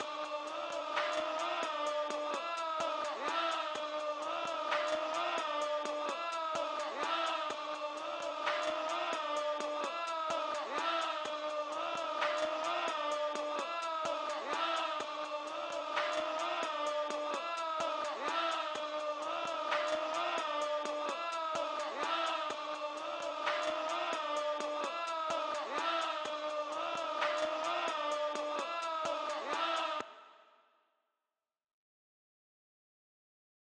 BWB [WAVE 2] 128 BPM VOX LOOP.wav